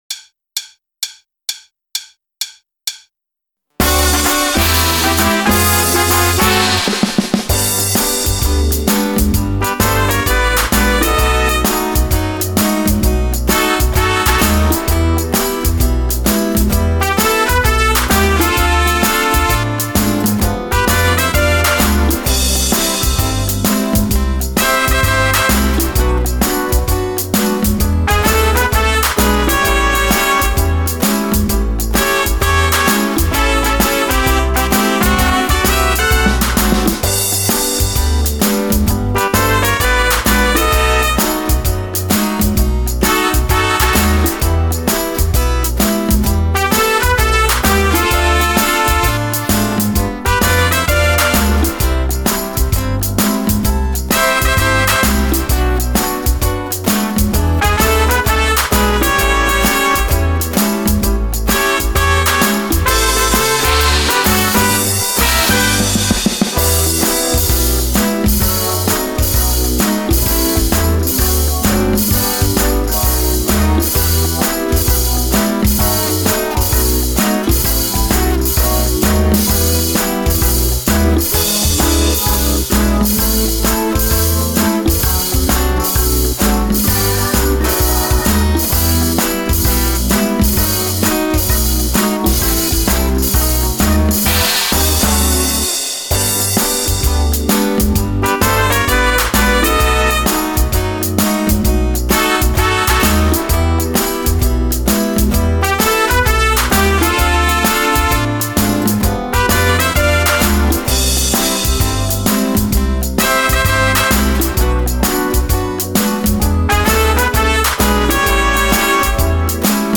Le Play Back
rythmique